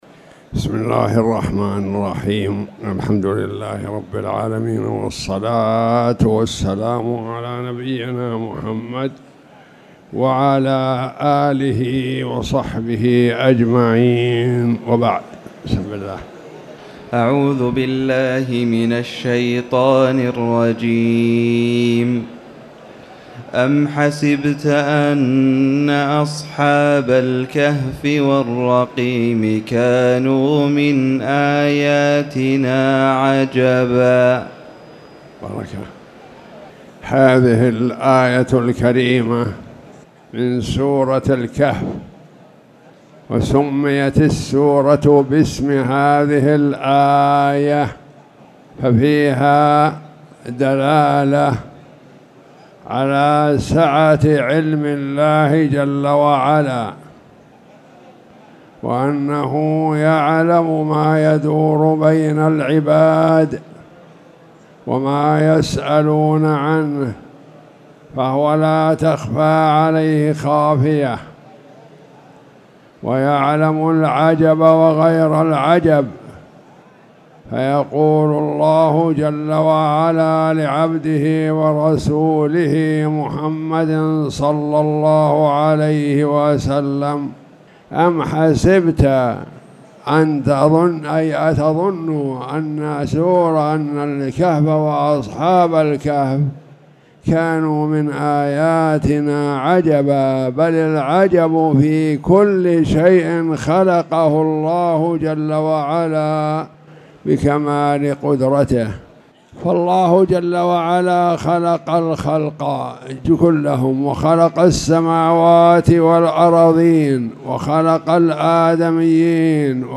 تاريخ النشر ١١ جمادى الأولى ١٤٣٨ هـ المكان: المسجد الحرام الشيخ